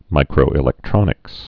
(mīkrō-ĭ-lĕk-trŏnĭks)